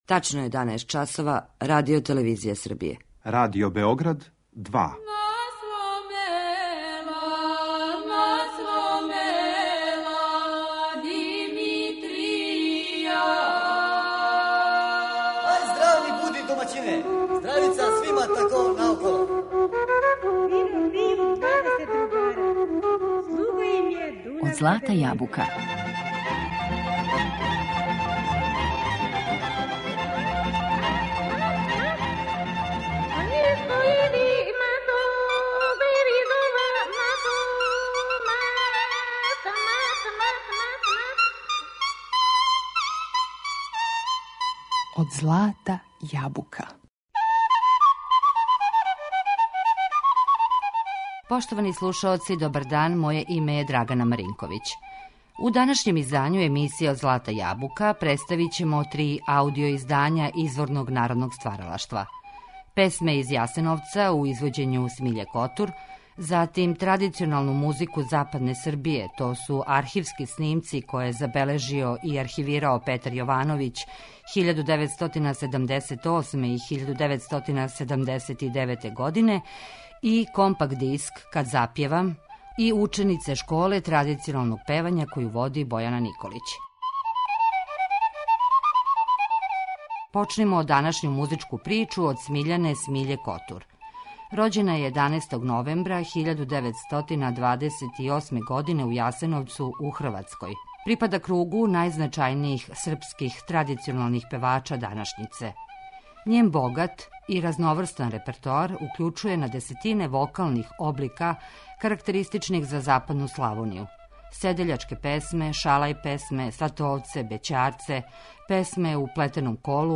Представићемо три аудио издања изворног народног стваралаштва